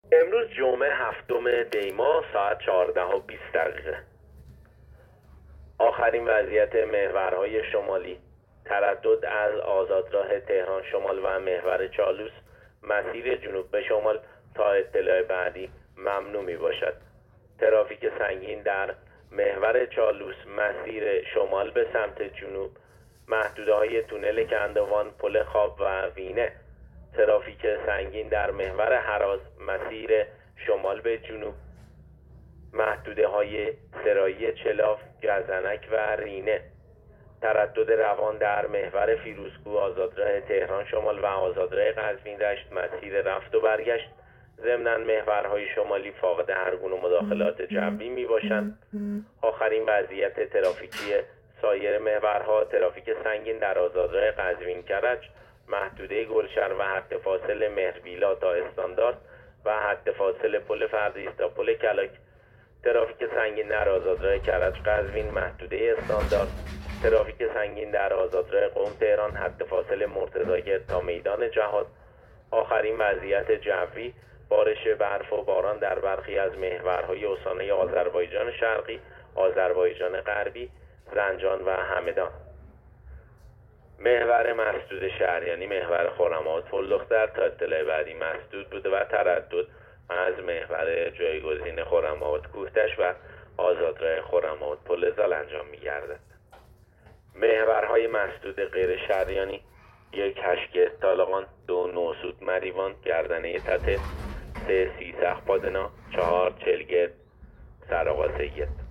گزارش رادیو اینترنتی از آخرین وضعیت ترافیکی جاده‌ها تا ساعت ۱۵ هفتم دی؛